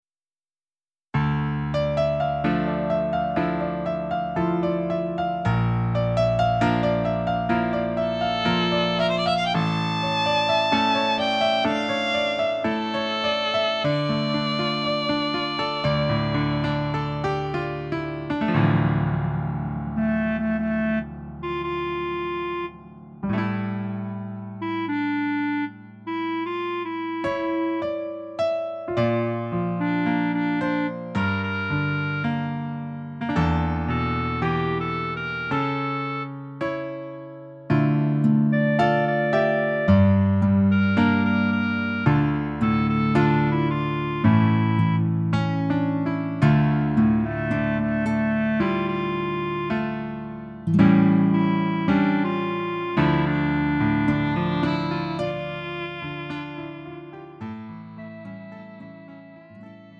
음정 -1키 3:39
장르 가요 구분 Lite MR